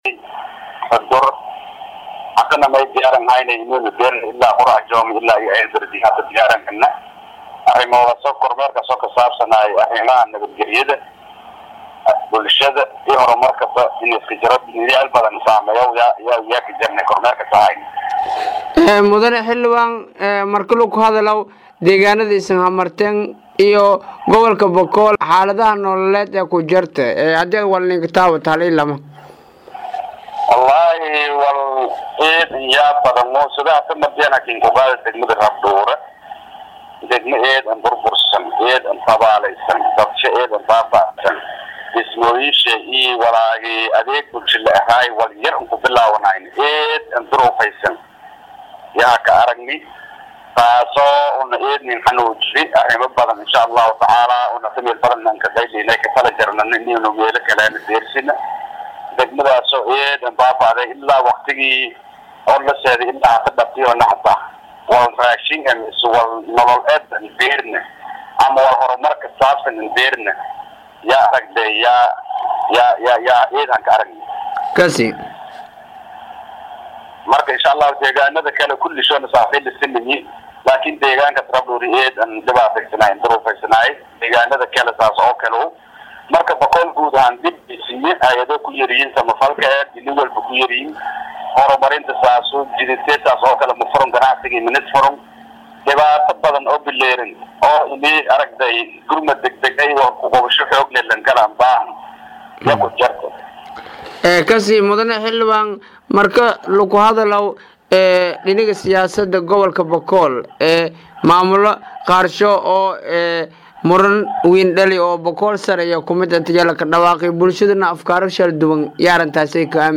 Xildhibaan Axmed  oo Wareysi Siyey Idale News Online ayaa sheegey in booqasho ku tageen Degmada Rabdhuure isla markaana waxa uu xusey in degmadaas ay ka maqan yihiin waxyaabaha aas aasiga ah ee bushada sidoo kale aysan la gaarsinin Gargaar Bini’aadanimo.